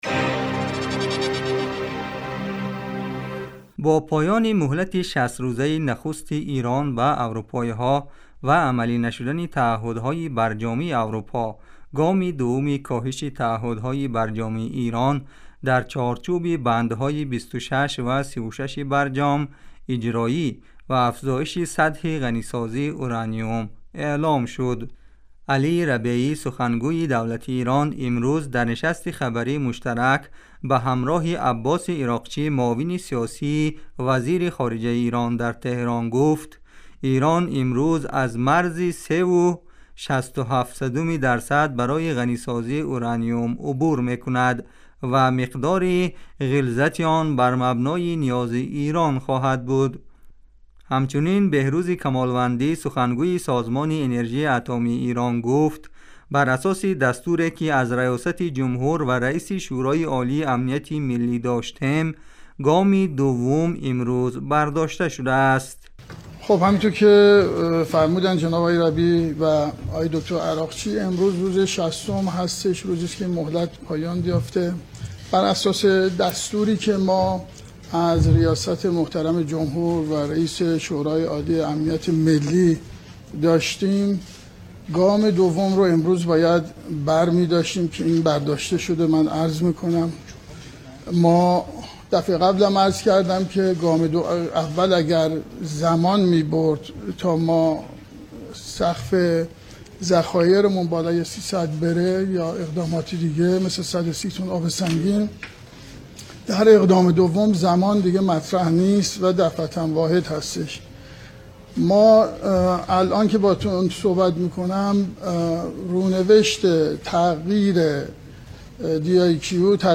Гузориши вижа. Оғози гоми дувуми Эрон дар БарҶом